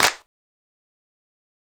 Tm8_Clap31.wav